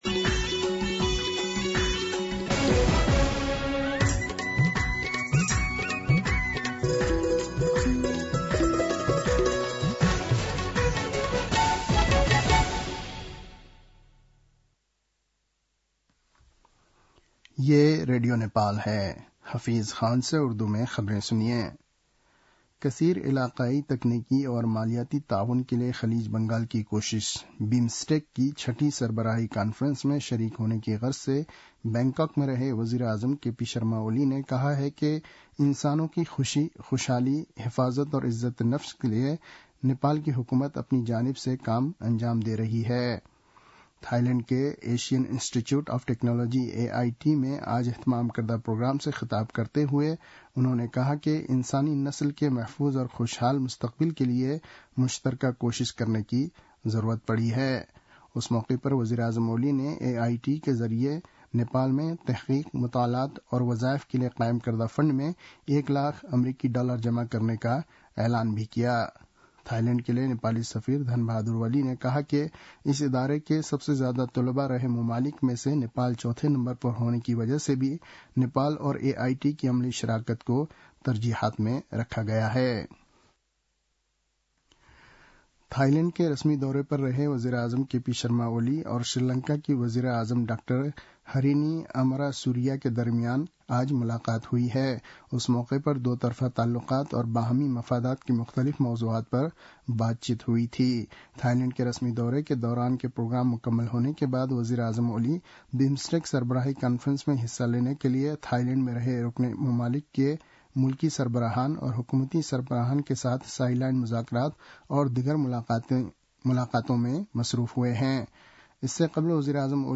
उर्दु भाषामा समाचार : २१ चैत , २०८१